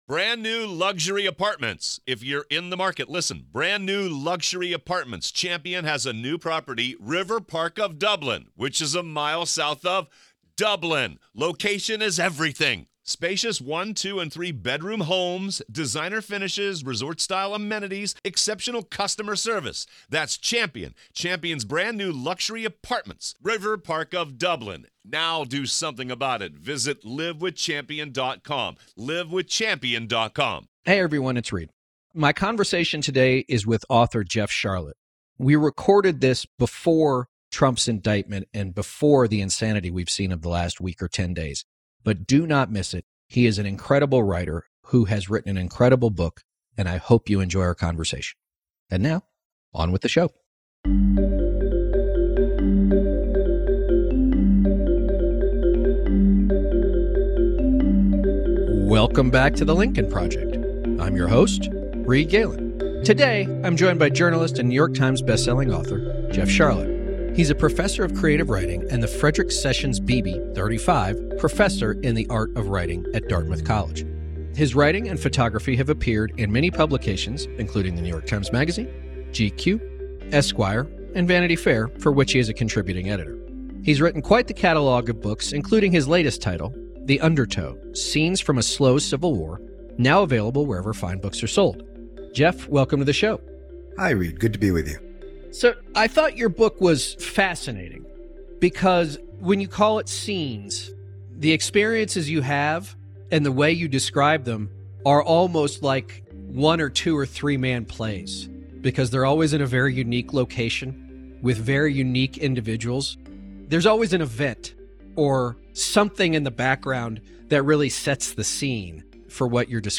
Host Reed Galen is joined by journalist and New York Times bestselling author, Jeff Sharlet.